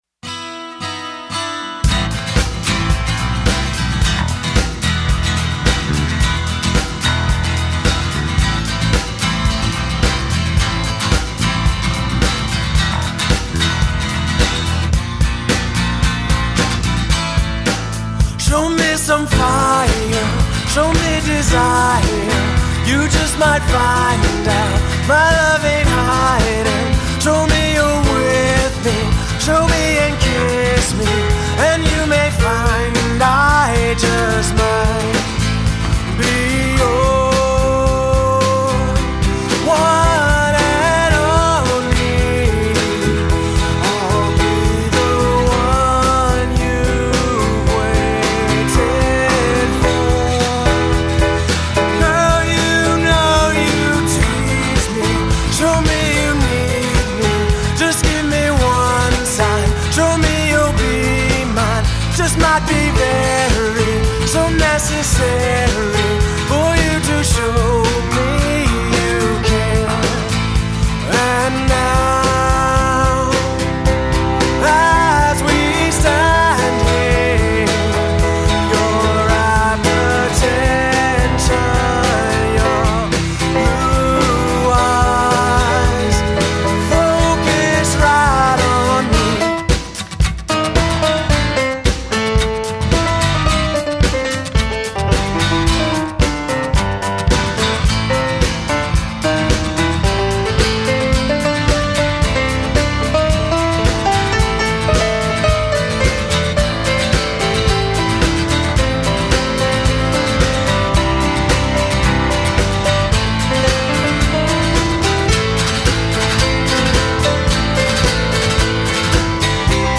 Guitar, Vocals
Drums
Piano, Keyboards
Bass Guitar